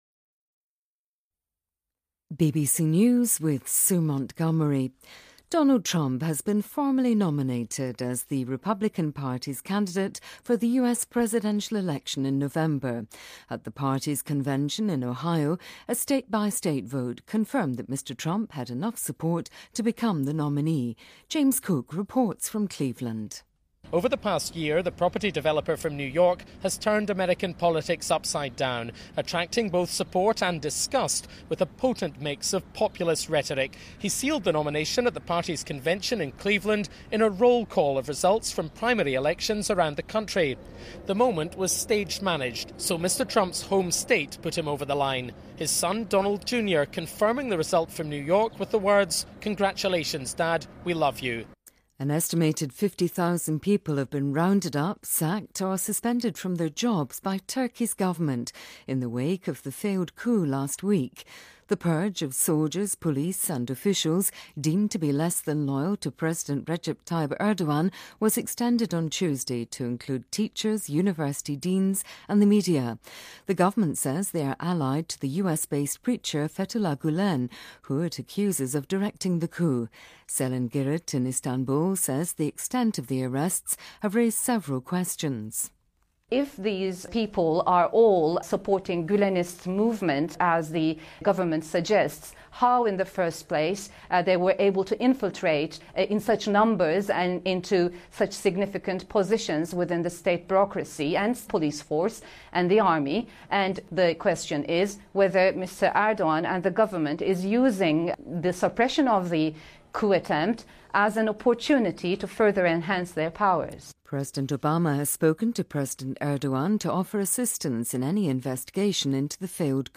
BBC news,2016年上半年的全球平均气温比工业化以前高出1.5°C